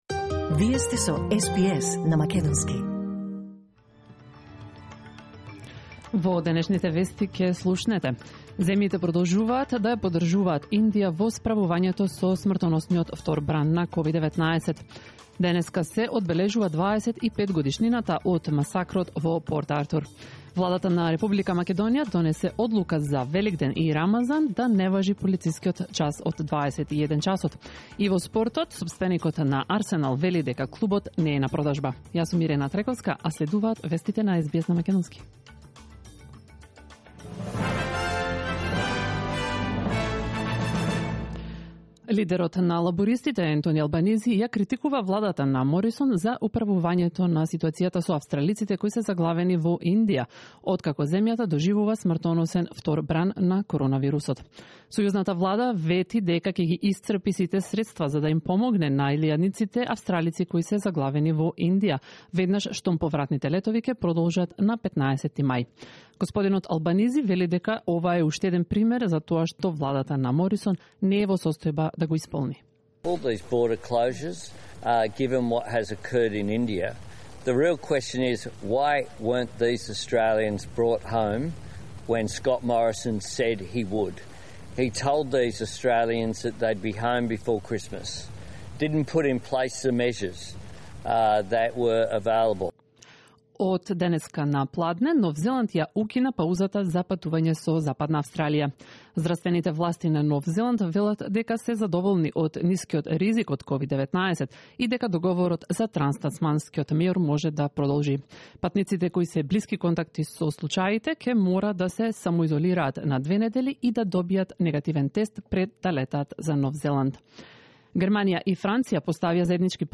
SBS News in Macedonian 28 April 2021